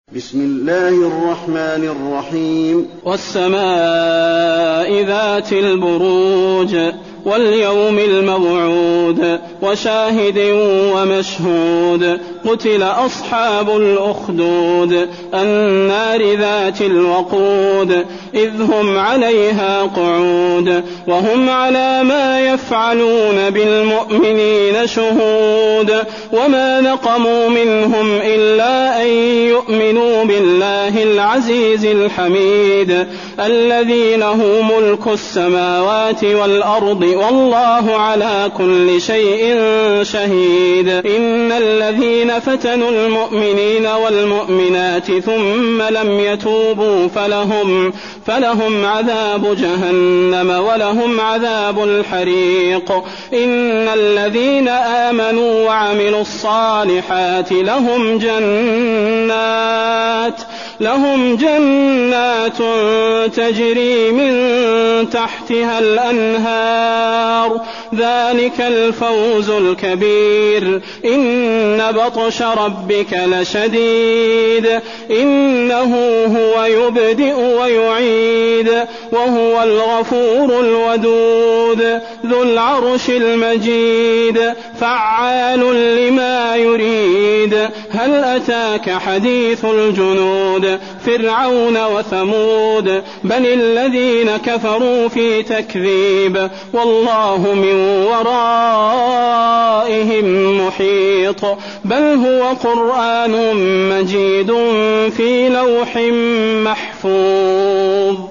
المكان: المسجد النبوي البروج